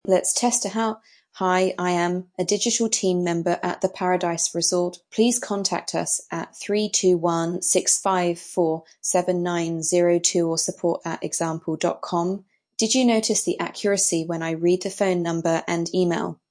Standard Voices for Realtime streaming
British
feminine, smooth, calm, professional